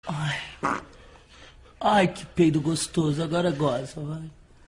Play, download and share que peido^ original sound button!!!!